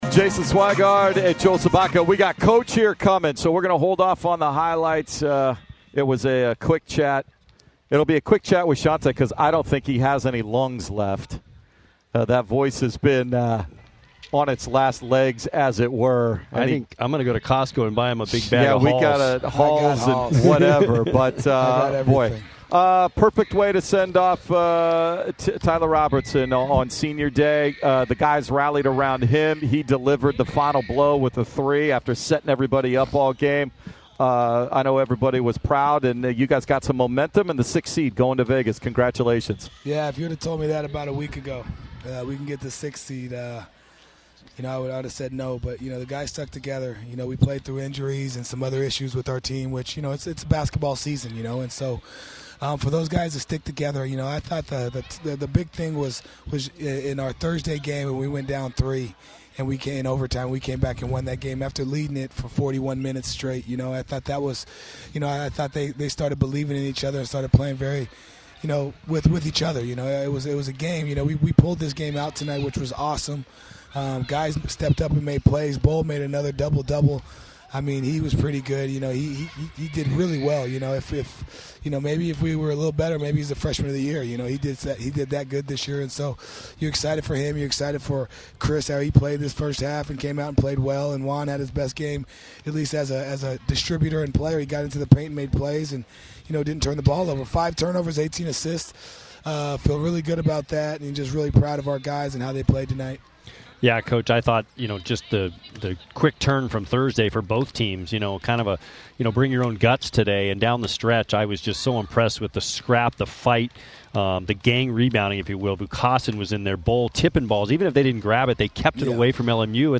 Men's Basketball Interviews